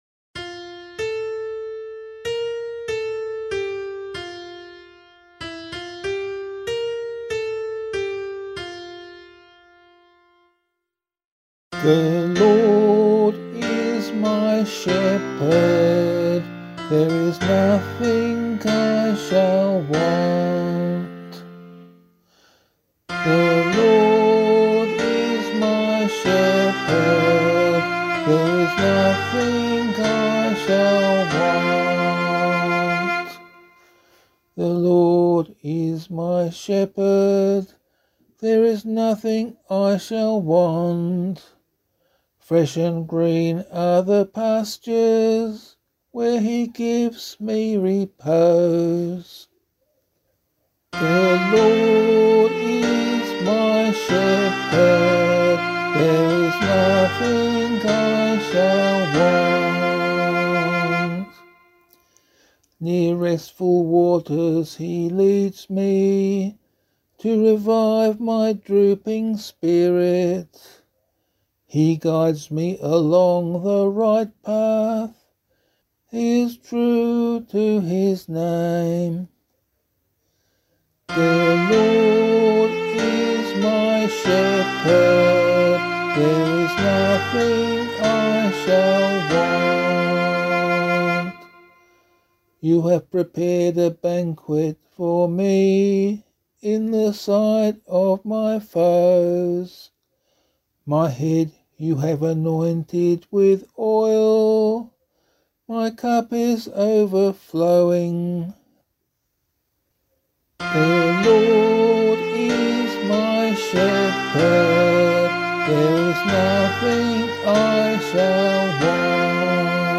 068 Christ the King Psalm A [LiturgyShare 6 - Oz] - vocal.mp3